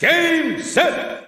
An example of Brawl foreign announcer calls.
Korean_Announcer_(Brawl).ogg.mp3